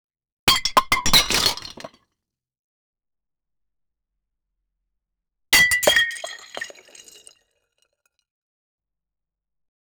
Breaking Clay Pot Sound Effect
breaking-clay-pot-sound-e-fjdpc6c5.wav